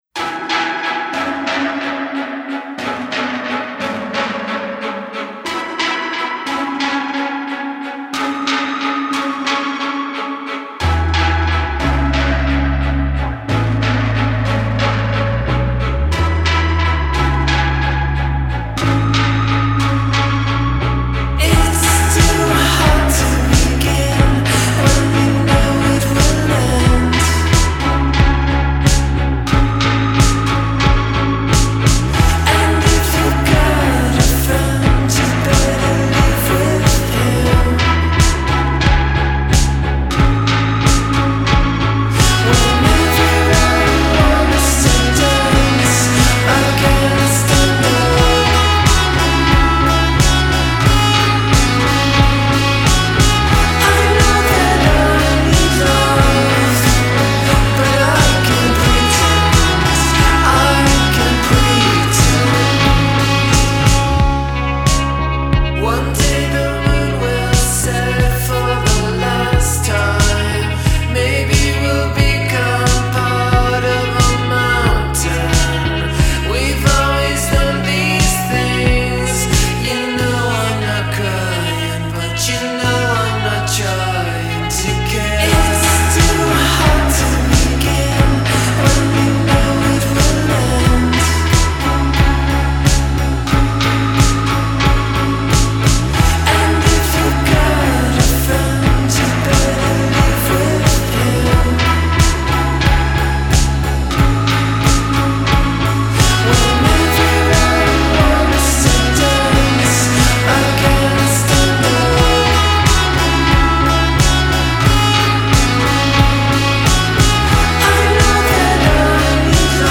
'60s pop melodies drive the post punk-filtered tunes